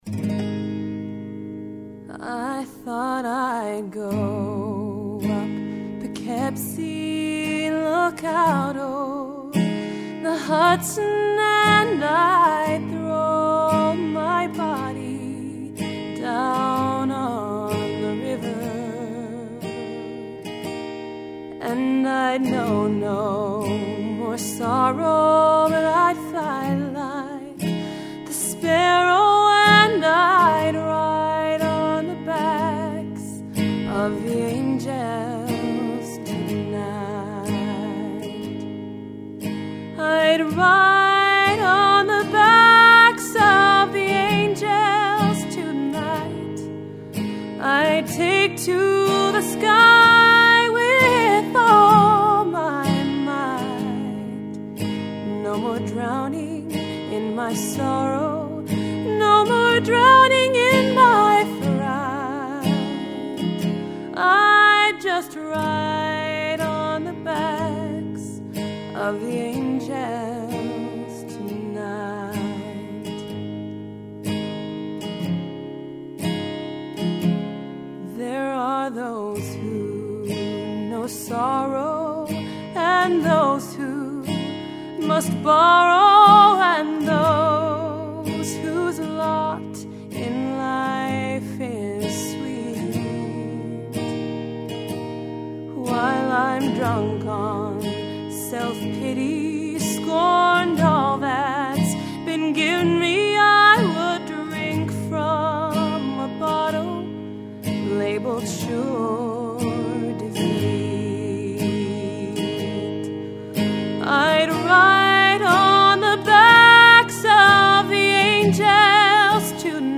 1) These are all first takes.